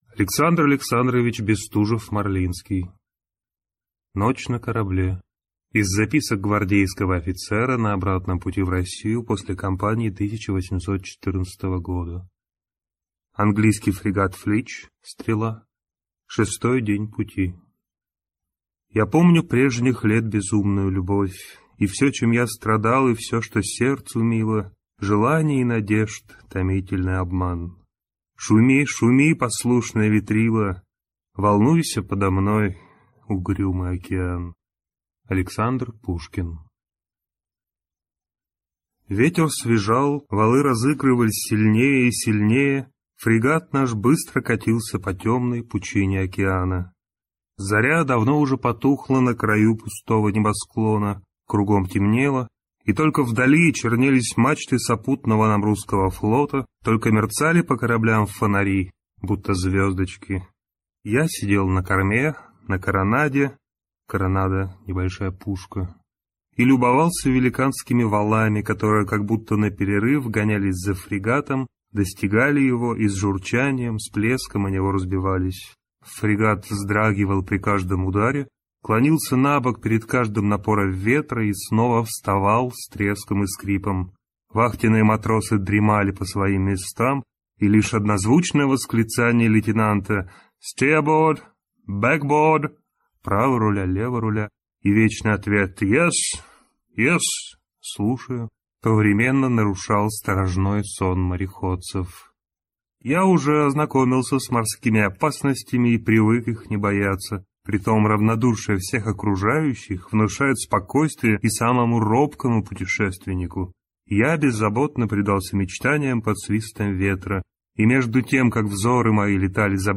Аудиокнига Ночь на корабле | Библиотека аудиокниг